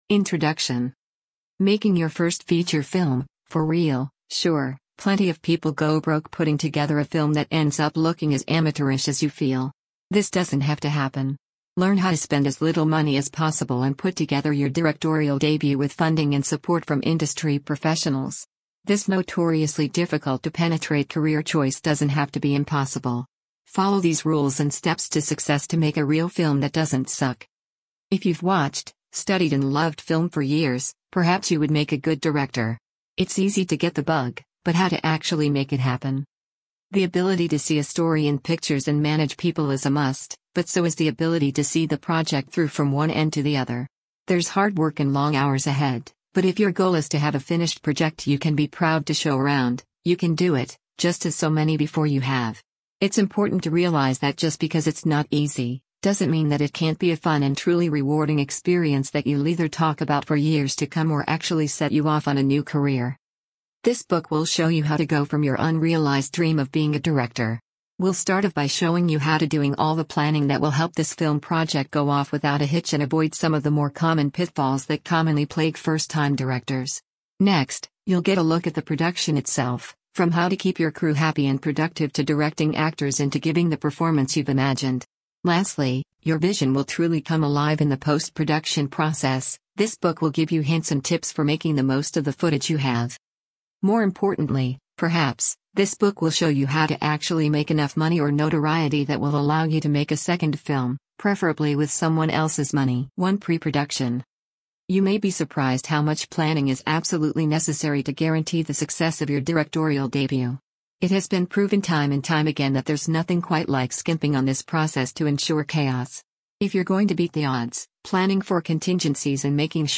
Featuring interviews with professional directors, behind-the-scenes insights, and practical tips, this podcast is your ultimate guide to building a successful career in film direction and turning your creative ideas into cinematic reality.